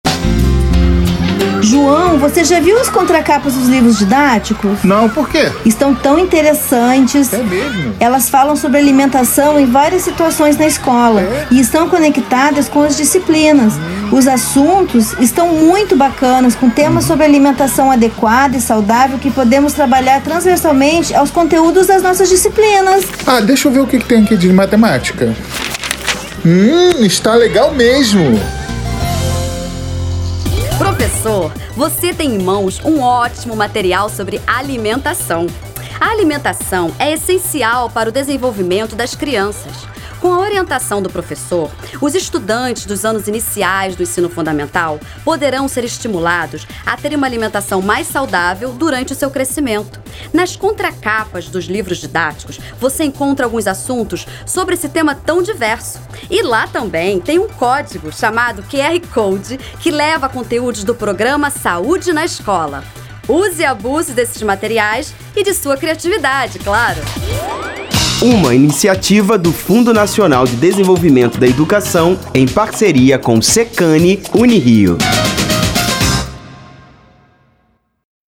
Spot de rádio - Ensino Fundamental 1